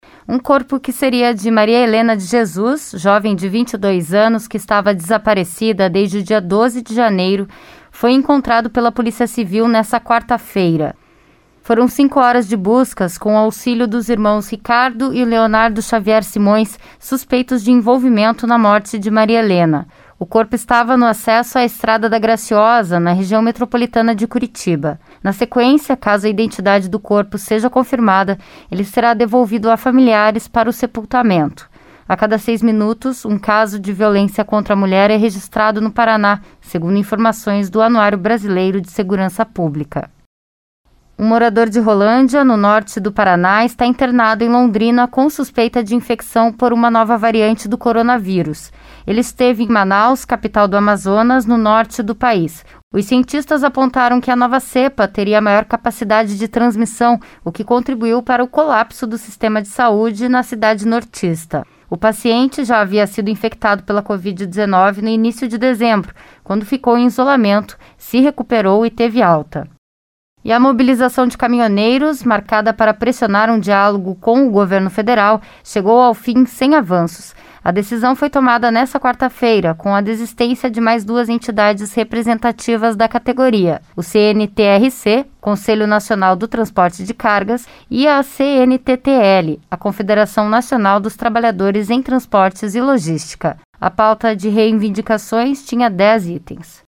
Giro de Notícias SEM TRILHA